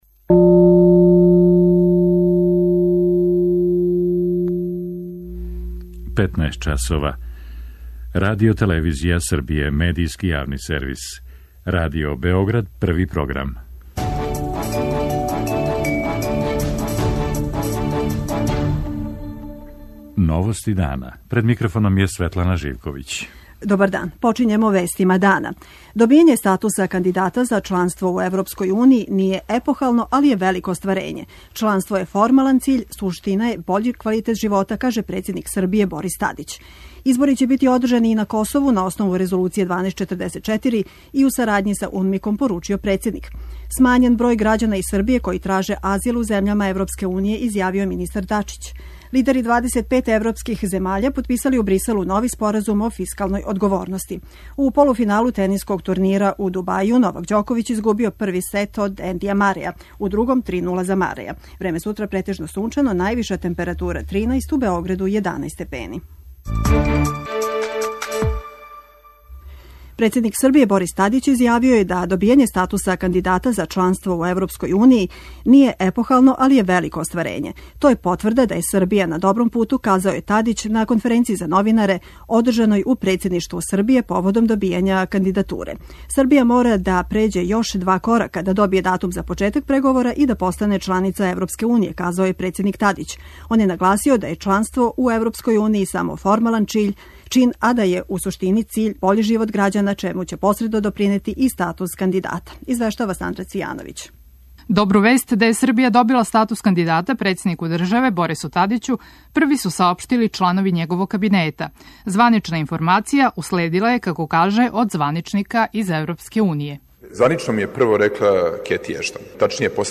Шеф делегације ЕУ у Београду Венсан Дежер изјавио је гостујући на нашем програму како Унија жели да види Србију ближе себи.
преузми : 15.65 MB Новости дана Autor: Радио Београд 1 “Новости дана”, централна информативна емисија Првог програма Радио Београда емитује се од јесени 1958. године.